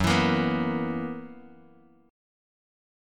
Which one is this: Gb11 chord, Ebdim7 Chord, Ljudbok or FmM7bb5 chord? Gb11 chord